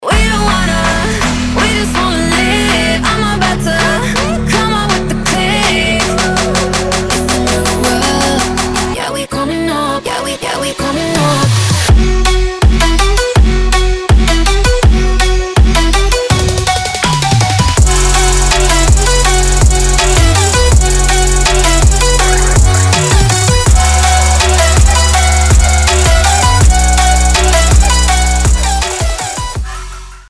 • Качество: 320, Stereo
женский вокал
dance
Electronic
EDM
Trap